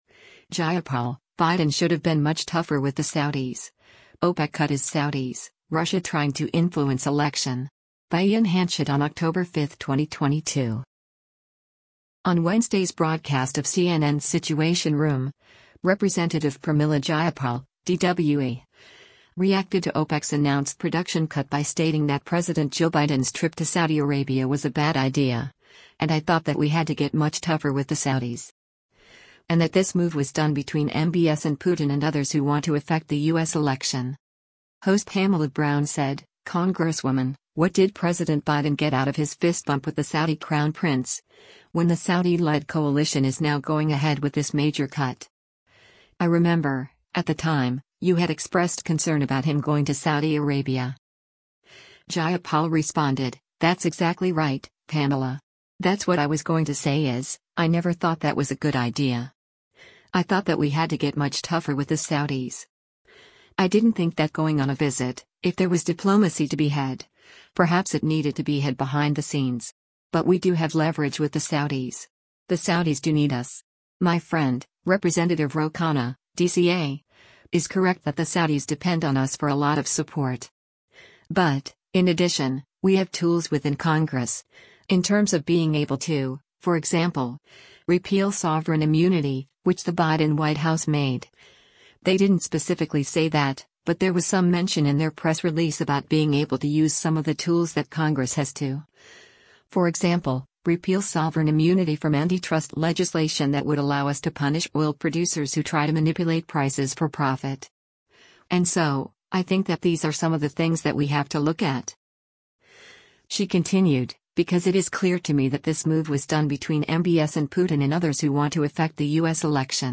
On Wednesday’s broadcast of CNN’s “Situation Room,” Rep. Pramila Jayapal (D-WA) reacted to OPEC’s announced production cut by stating that President Joe Biden’s trip to Saudi Arabia was a bad idea, and “I thought that we had to get much tougher with the Saudis.” And that “this move was done between MBS and Putin and others who want to affect the U.S. election.”